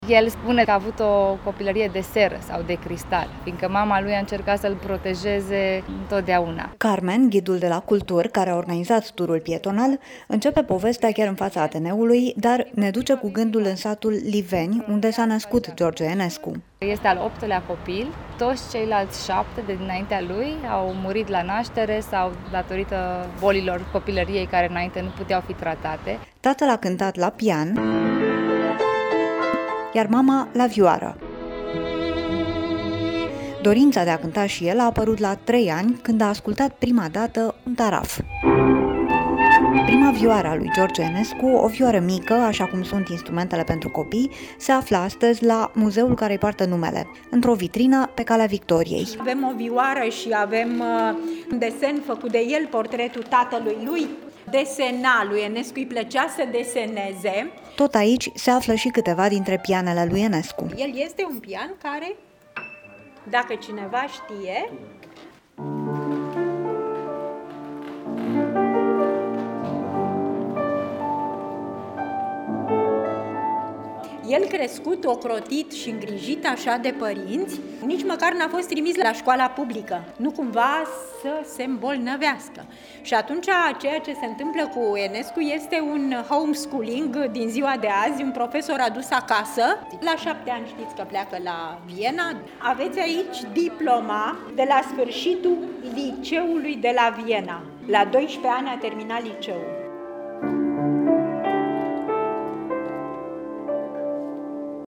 Reportaj: Pe urmele lui George Enescu (AUDIO)